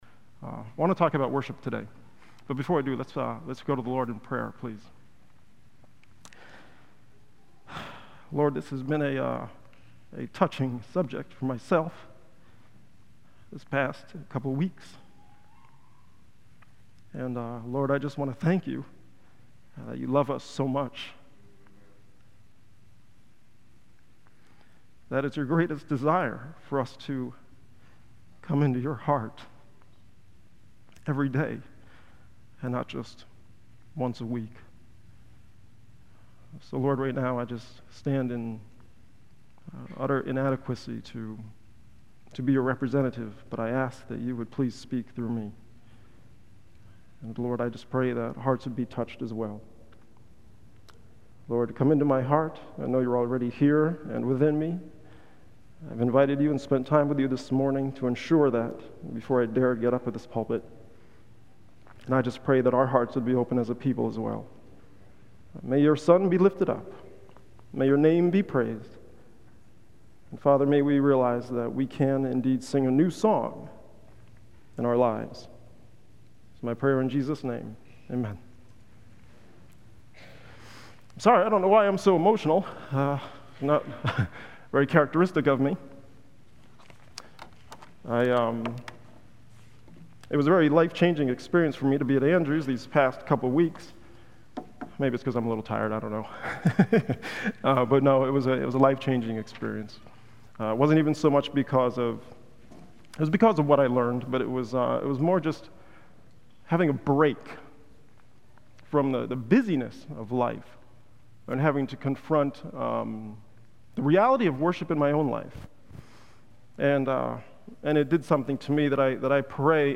on 2015-07-31 - Sabbath Sermons